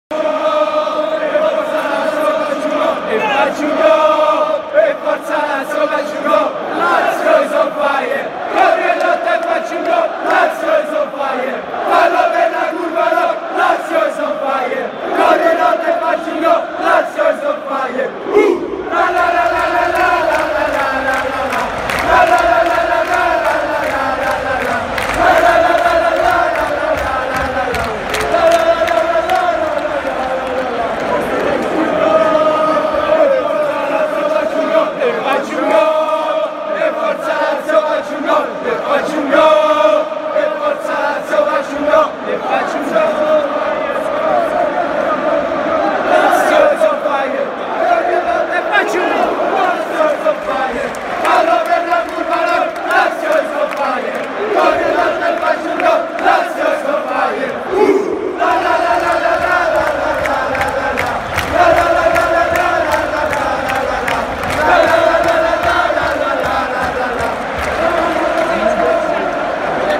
Qui troverete tutti i cori che si cantano allo Stadio.